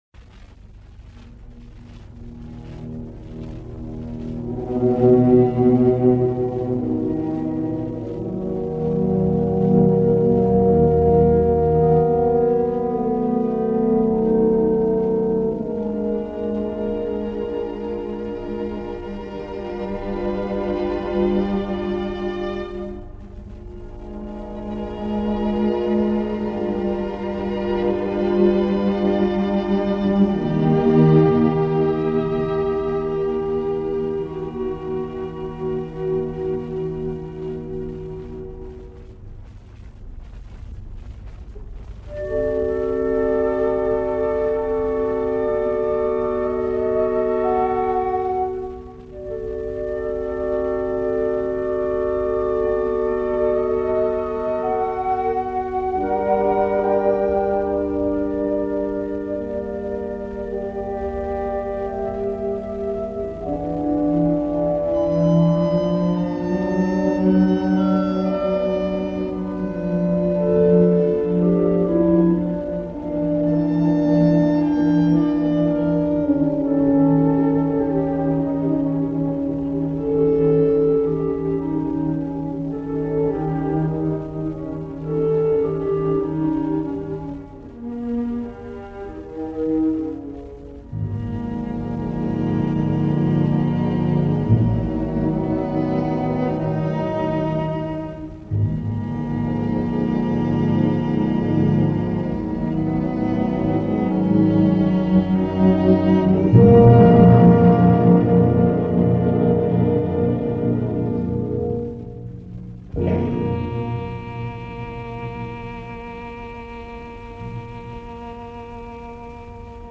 From the astonishing Archives of RTS, a live performance broadcast and recorded on March 17, 1941 featuring violinist Jacques Thibaud and Orchestre de la Suisse Romande conducted by Ernest Ansermet of Ernest Chausson’s Poeme for Violin and Orchestra.